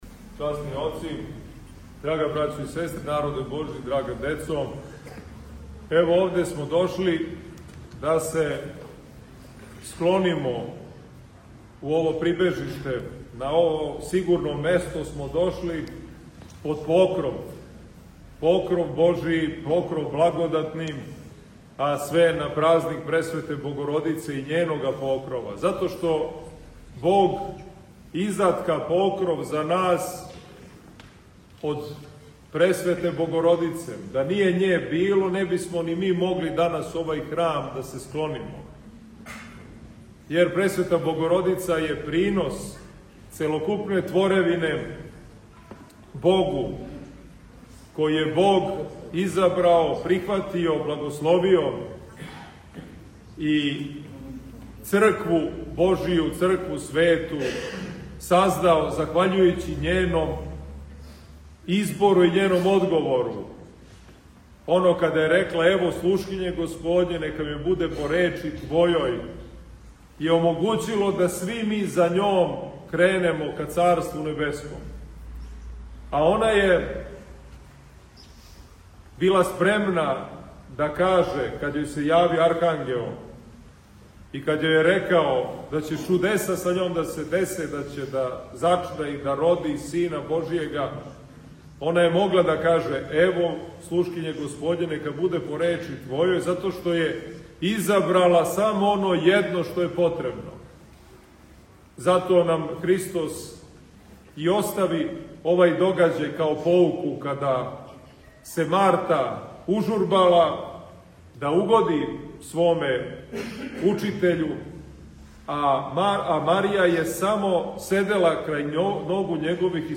У оквиру серијала „Са амвона“, доносимо звучни запис беседе коју је Његово Преосвештенство Епископ новобрдски г. Иларион, викар Патријарха српског, изговорио у дан спомена Покрова Пресвете Богородице, 1 / 14. октобра 2025. године. Епископ Иларион је беседио на светој Литургији у храму Покрова Пресвете Богородице у Баричу надомак Београда.